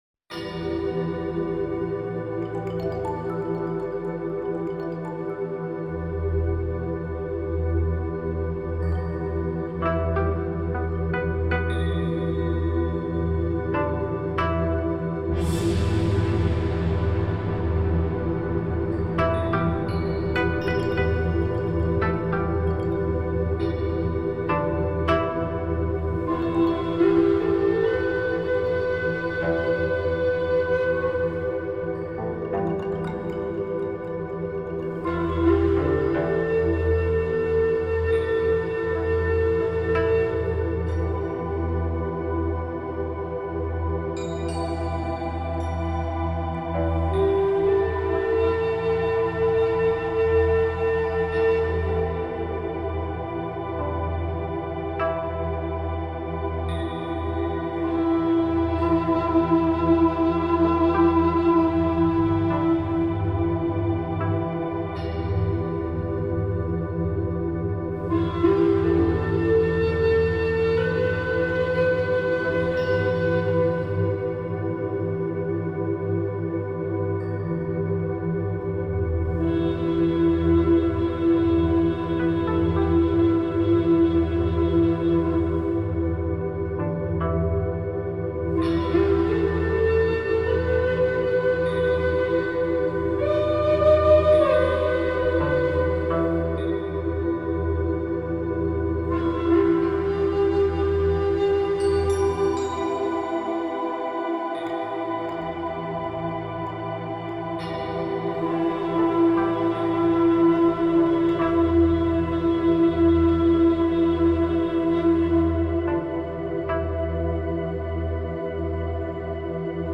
آرامش بخش , عصر جدید , مدیتیشن , موسیقی بی کلام